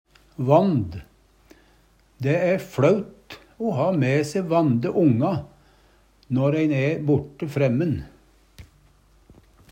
vand - Numedalsmål (en-US)